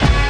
SWINGSTAB 13.wav